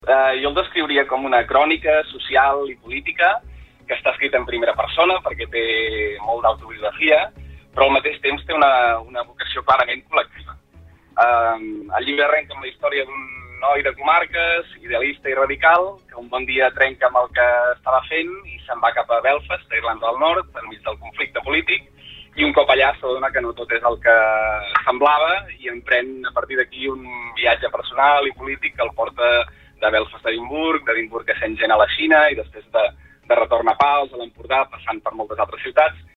EntrevistesSupermatí